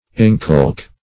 inculk - definition of inculk - synonyms, pronunciation, spelling from Free Dictionary
Search Result for " inculk" : The Collaborative International Dictionary of English v.0.48: Inculk \In*culk"\ v. t. [Cf. F. inculquer.